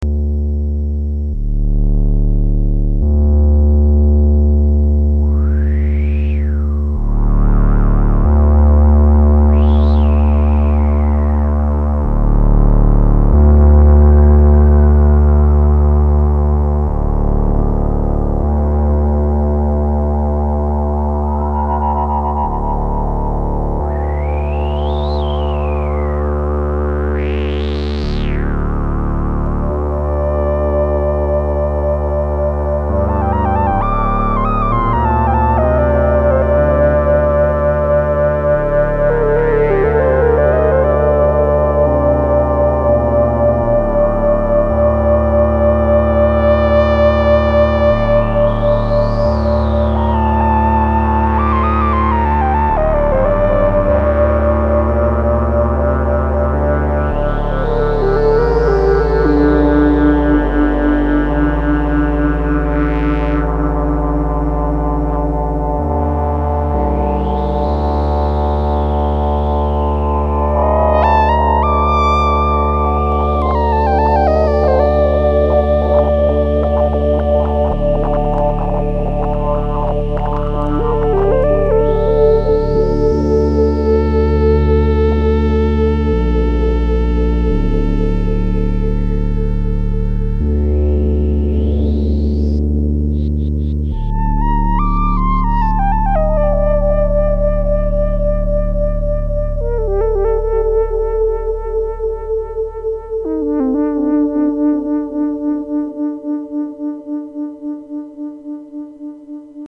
Synthstuff Jen SX1000